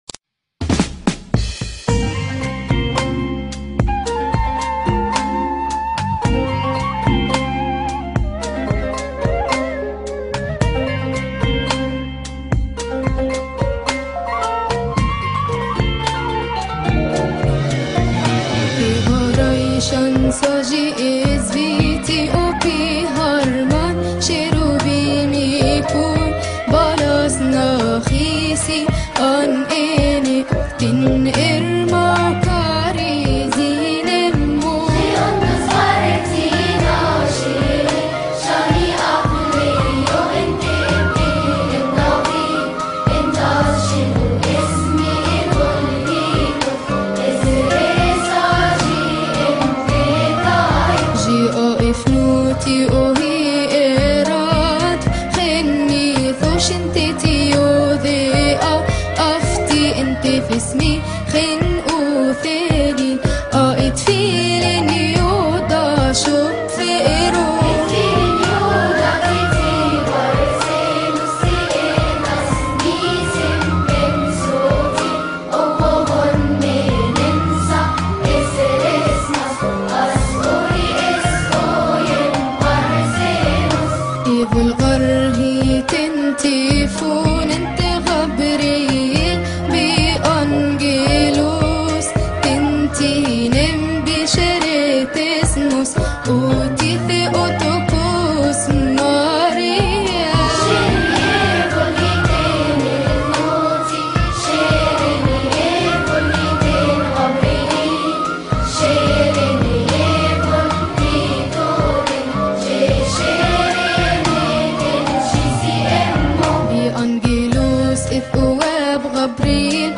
استماع وتحميل لحن ذكصولوجية كيهك كى غار من مناسبة keahk